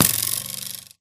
bowhit4.ogg